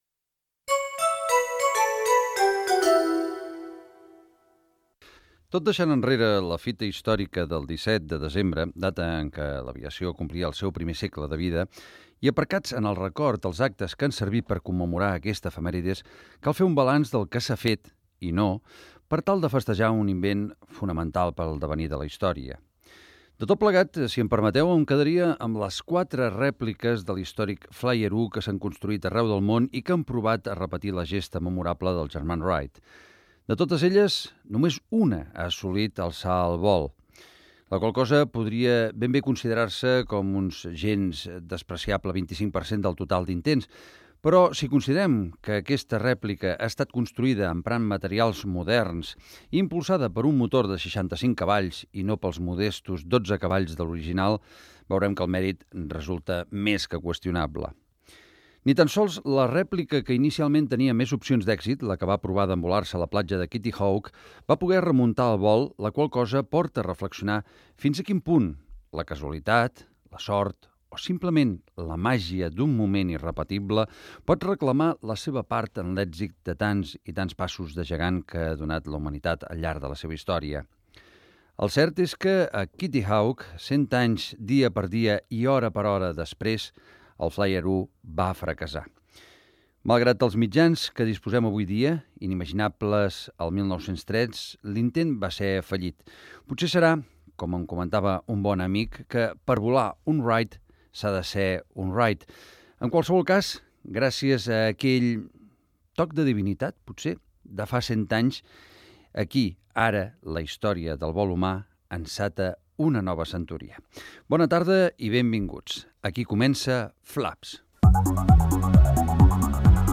El centenari de l'aviació, careta del programa, sumari, indicatiu, el Boogie de Nadal paracaigudista a Empuriabrava, publicitat
Divulgació
FM